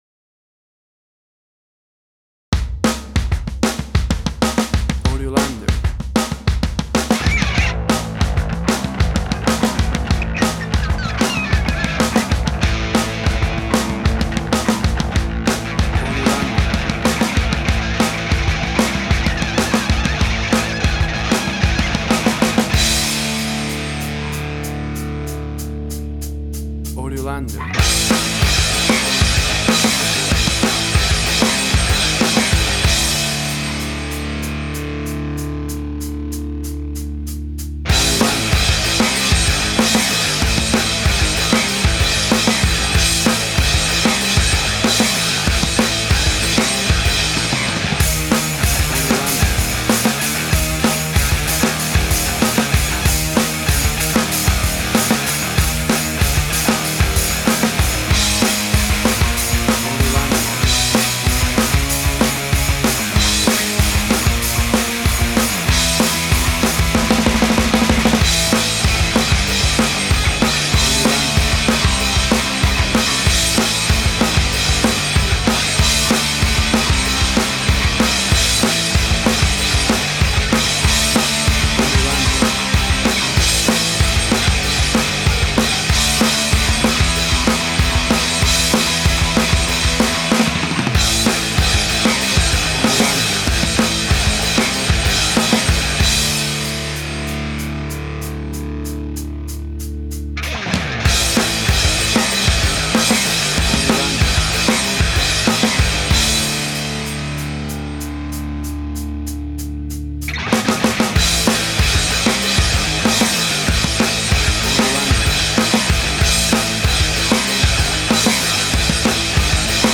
1990s style angry punk rock
Tempo (BPM): 190